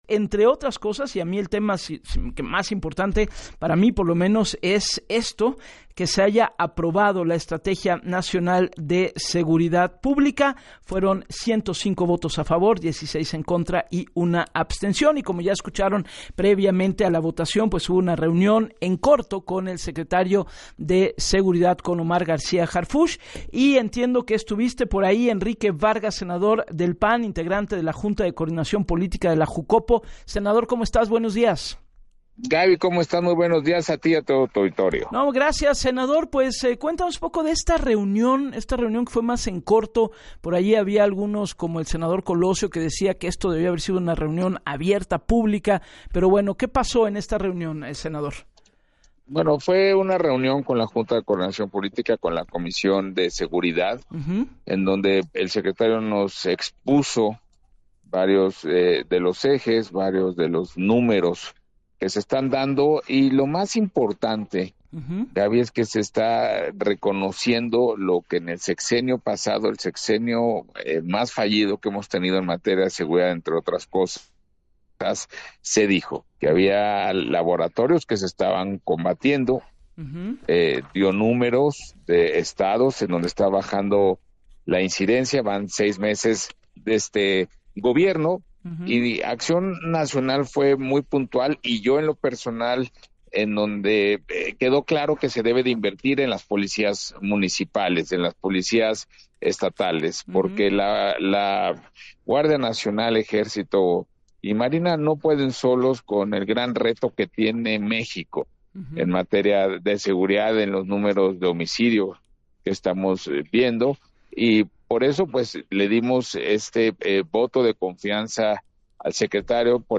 En entrevista para “Así las Cosas” con Gabriela Warkentin, detalló que García Harfuch habló del combate a los laboratorios clandestinos y dio números de los estados en donde está bajando la incidencia de inseguridad, por lo que Acción Nacional le dio su “voto de confianza y le dejó claro que se debe invertir en las policías municipales y estatales porque la Guardia Nacional y la Marina no puede solos”.